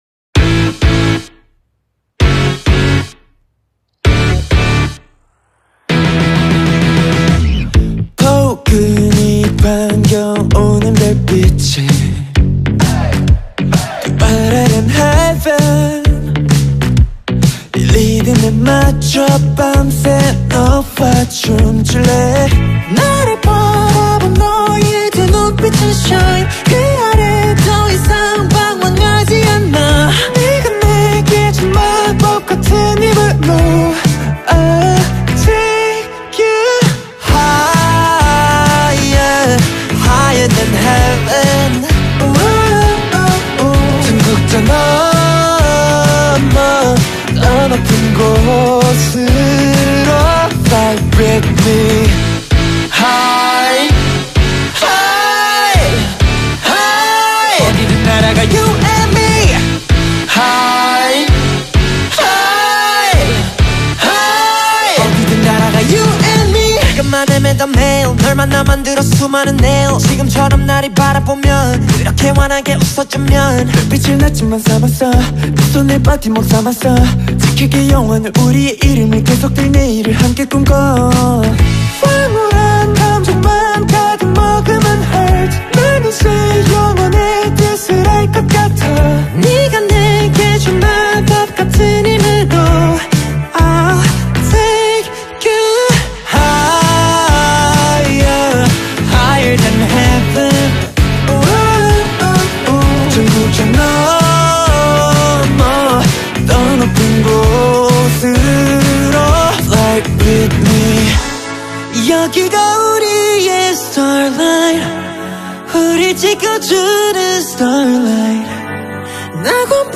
Скачать музыку / Музон / Корейская K-POP музыка 2024